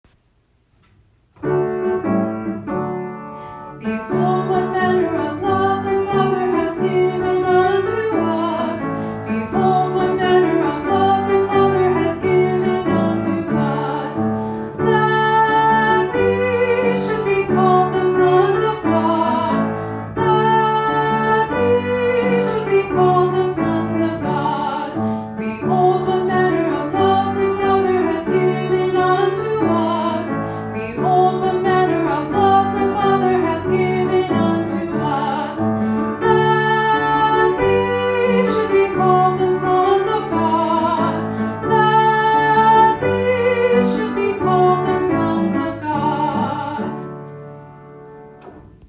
Scripture Songs